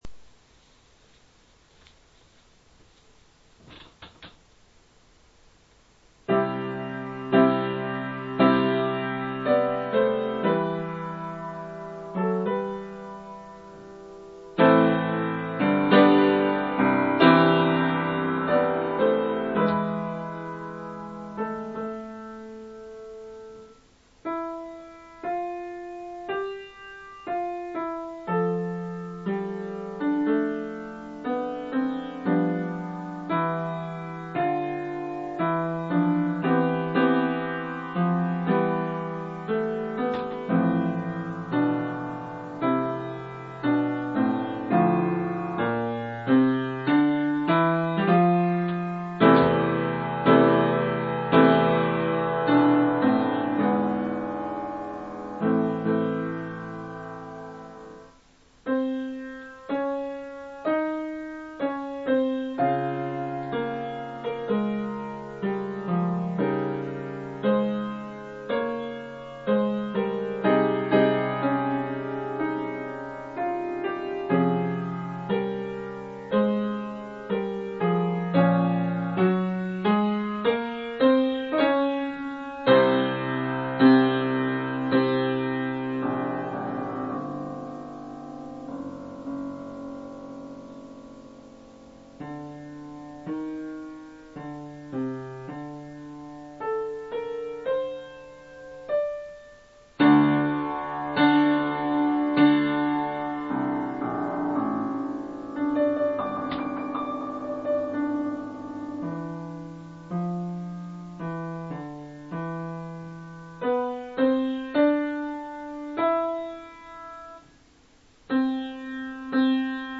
会議用の簡易録音機器なので、音楽を録ると音が割れやすく、使いにくい。